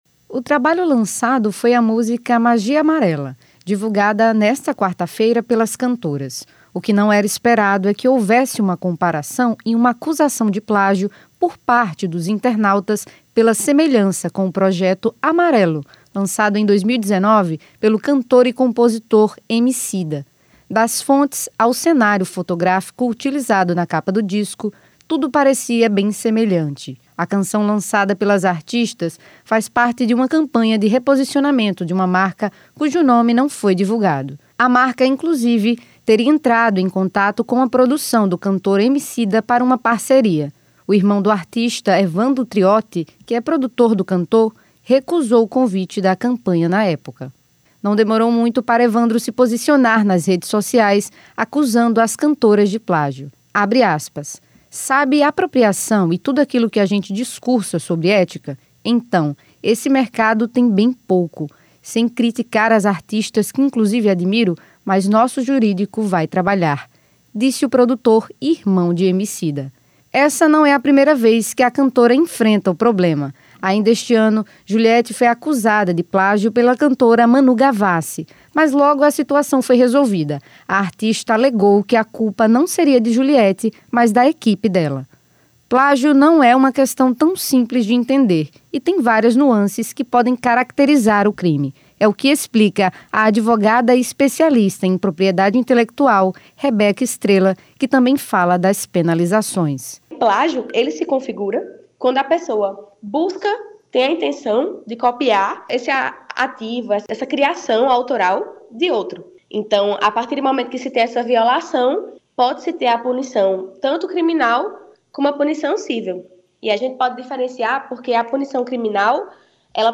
Reportagem: Juliete sofre acusação de plágio